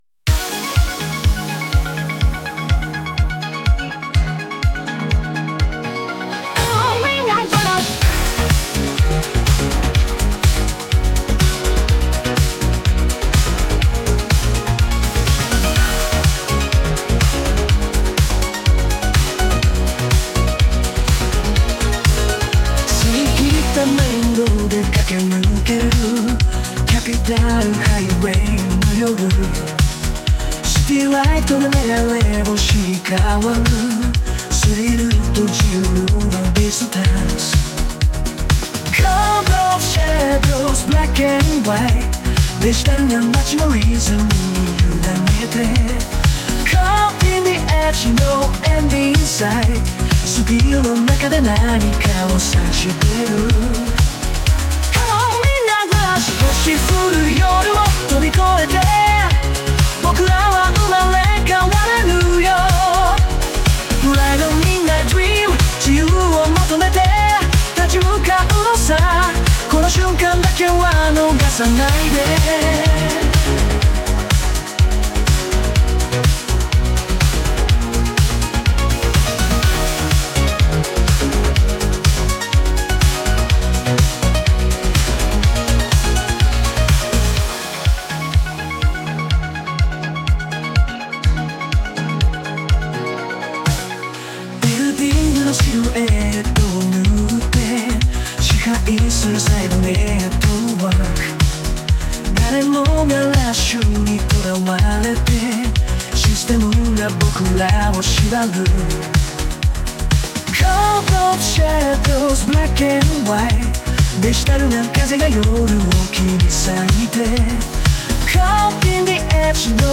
AI生成 懐メロ音楽集